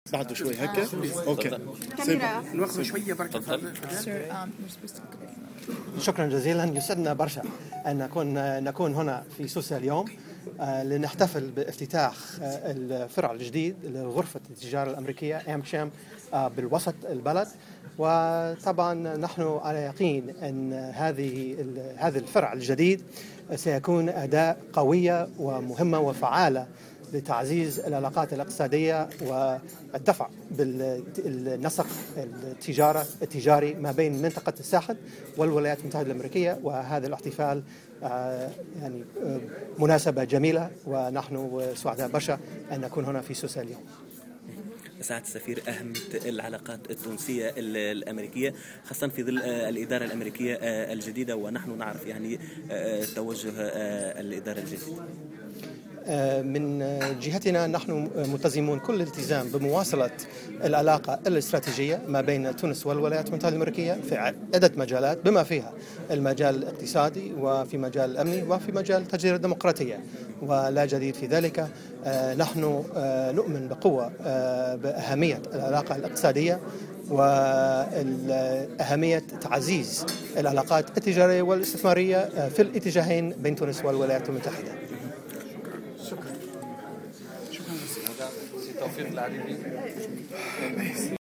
وقال في تصريح للجوهرة "اف ام" على هامش زيارة أداها إلى سوسة إن هذه الفرع الجديد سيعمل على تعزيز العلاقات الاقتصادية بين منطقة الساحل والولايات المتحدة، بحسب تعبيره.